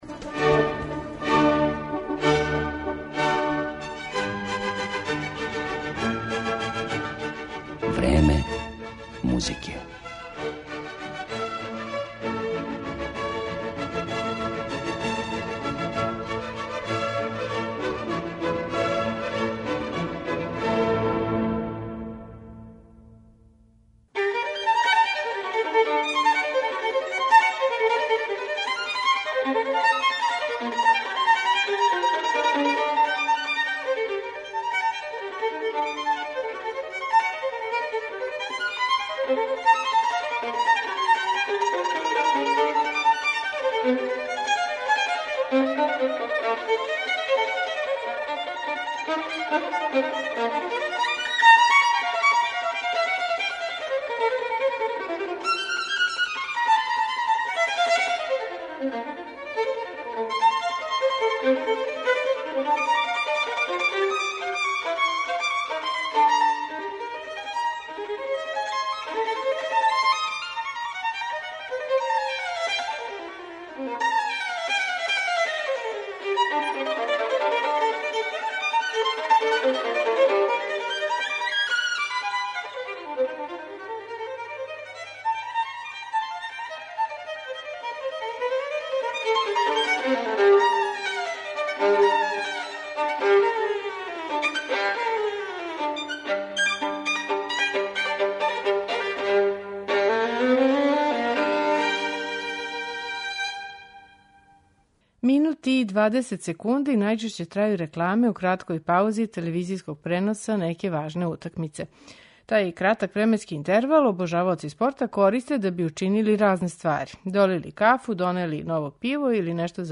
Виолиниста